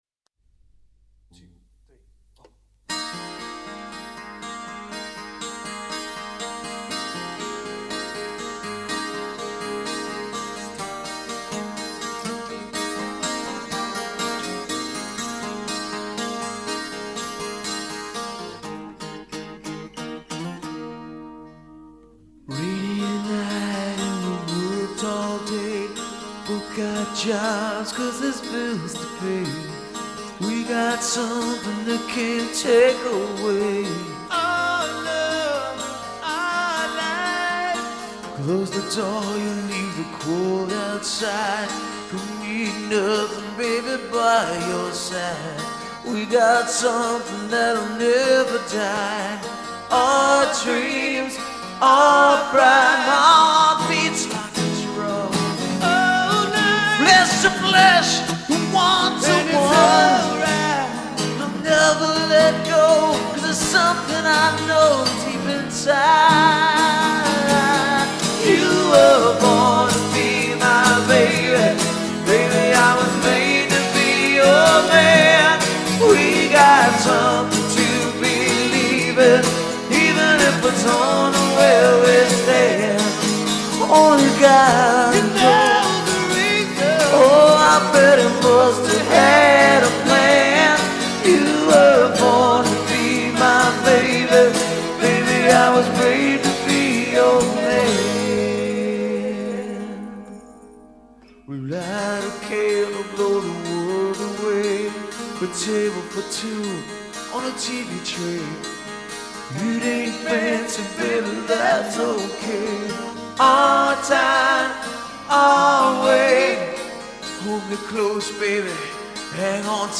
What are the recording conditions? acoustic demo